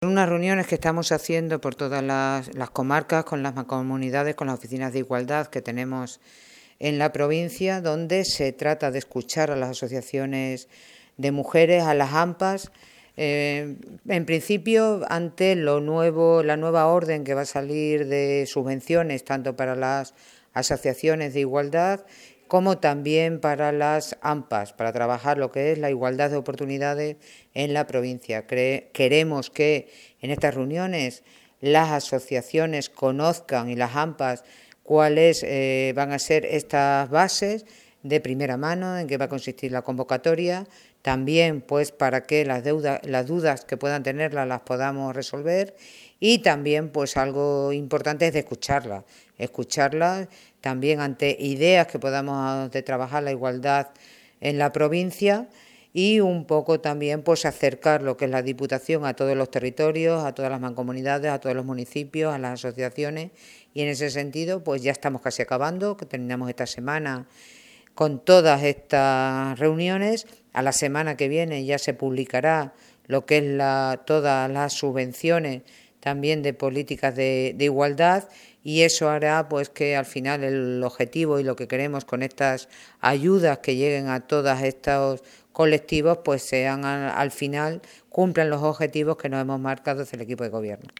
CORTES DE VOZ
Son palabras de Charo Cordero, presidenta de la Diputación Provincial de Cáceres en la Asamblea de Mujeres que se ha celebrado en Campo Arañuelo, en la localidad de Peraleda de la Mata.